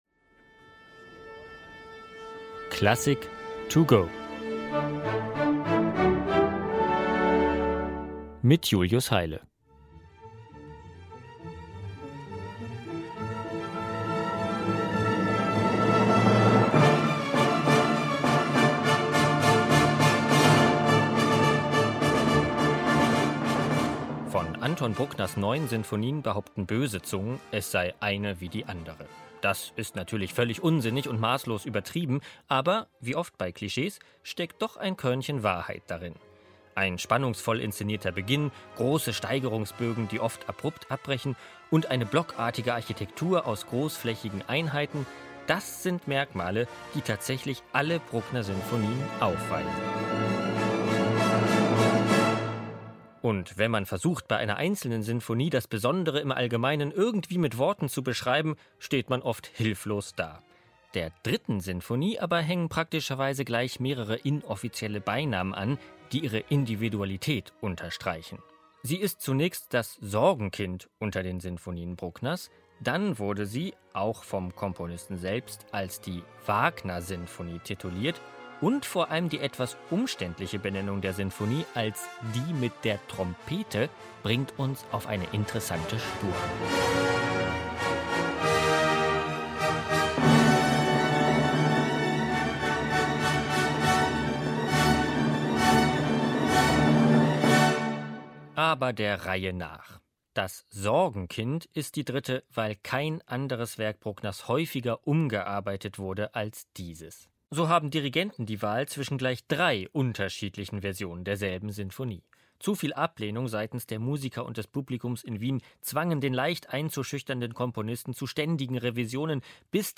Kurzeinführung